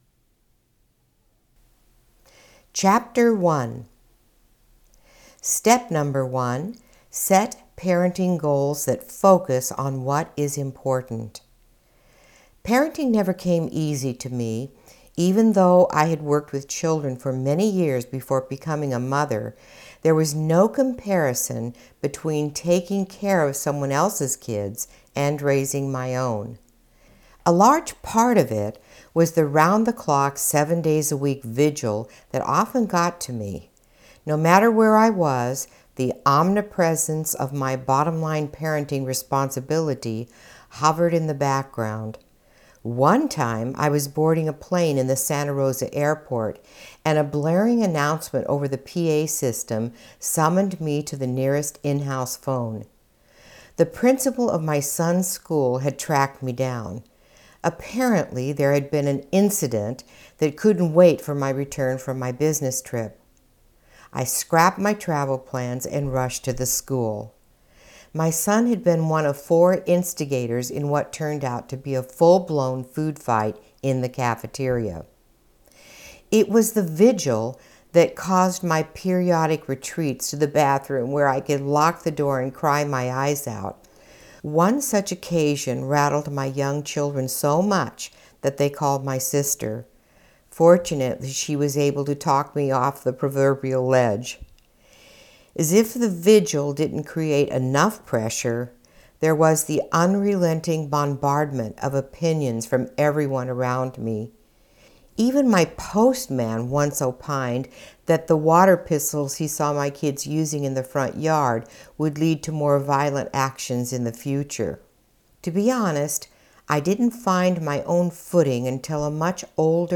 AUDIO BOOK ABOUT PARENTING GOALS FOR PARENTS